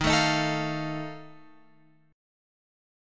D# Suspended 2nd Flat 5th